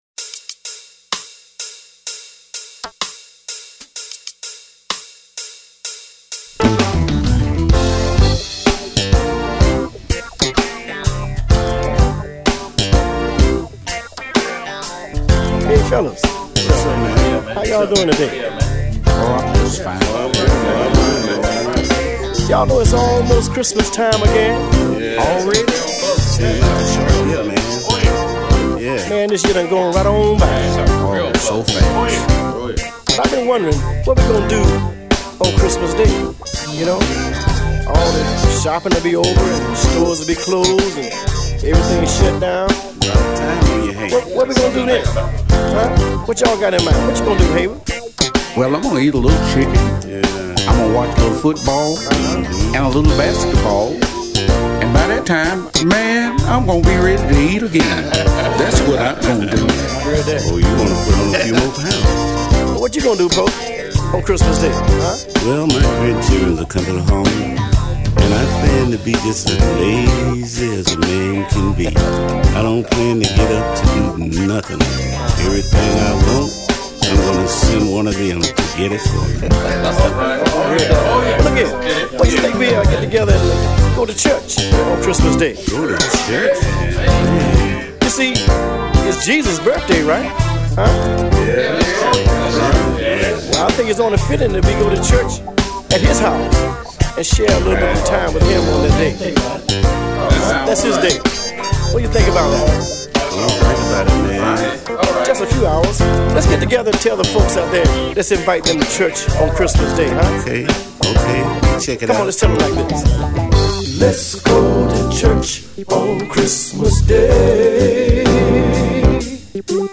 Christmas music
quartet material